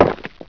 STONEHIT.WAV